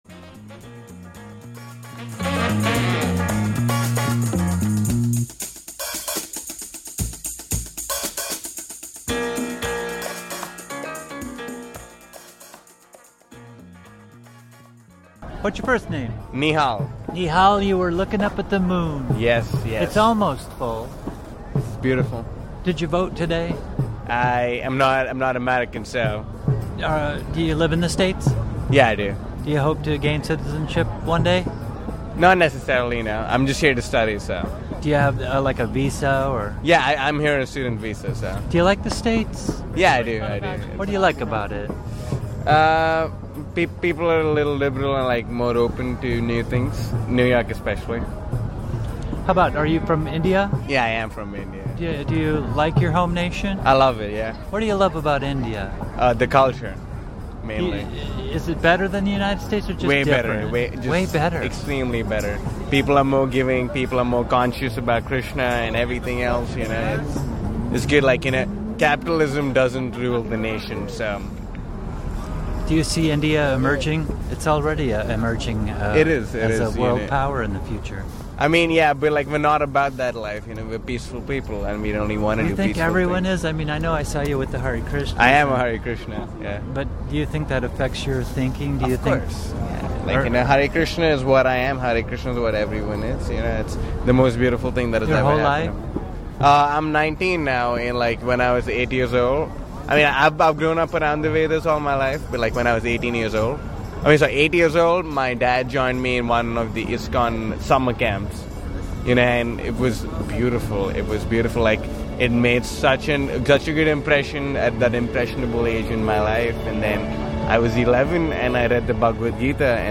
Post MidTerm Meltdown - Street Talk - Music - Bytes - Non-Voters from Nov 6, 2014